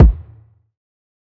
TC Kick 03.wav